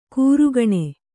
♪ kūrugaṇe